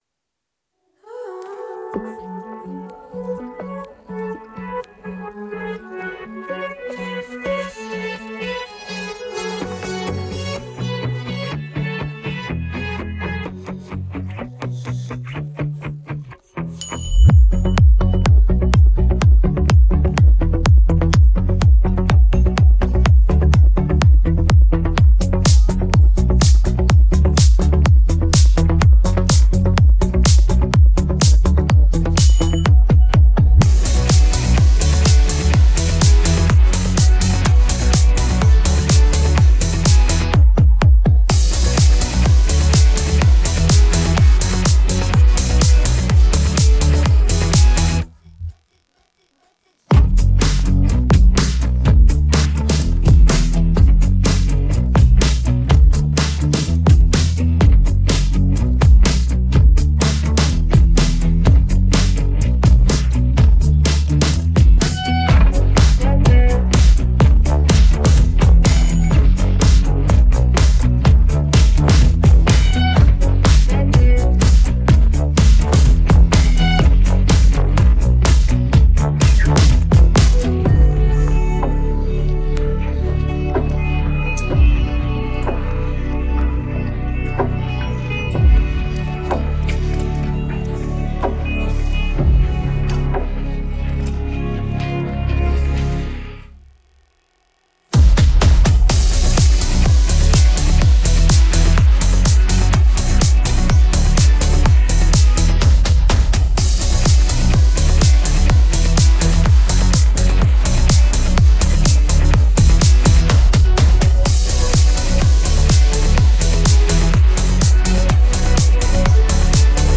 караоке
🎵 минусовка